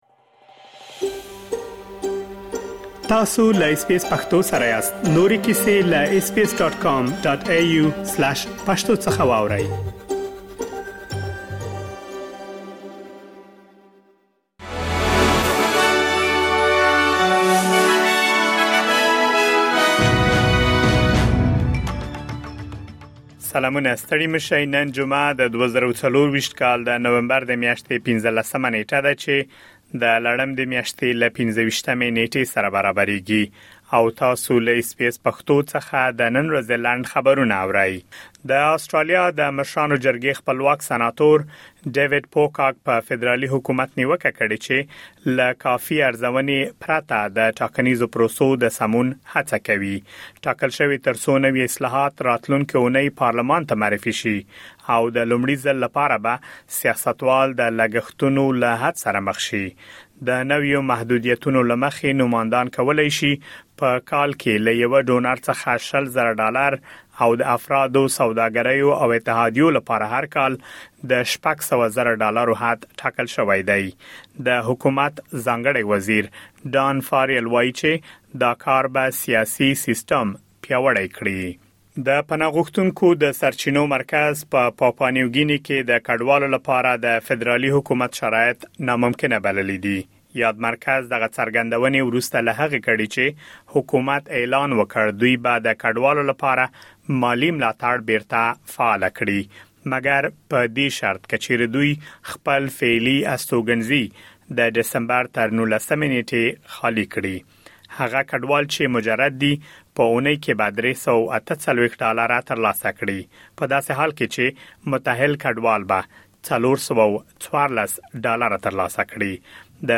د اس بي اس پښتو د نن ورځې لنډ خبرونه |۱۵ نومبر ۲۰۲۴